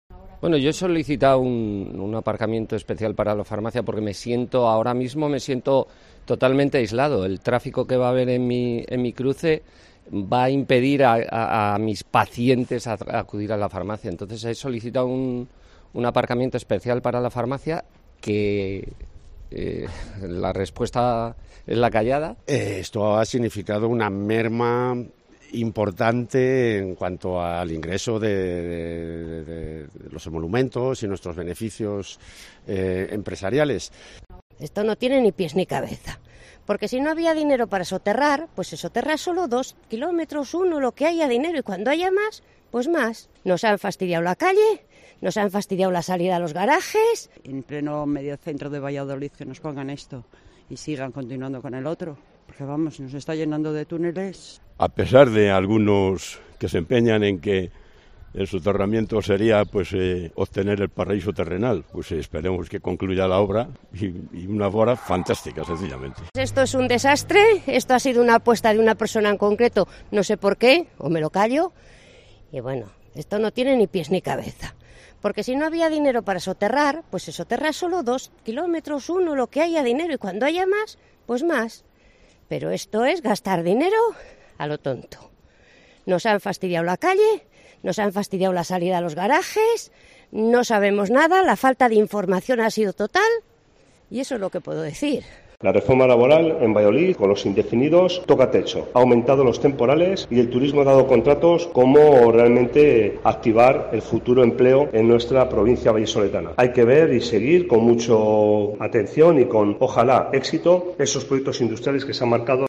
Vecinos y comerciantes ante la apertura del túnel de Panaderos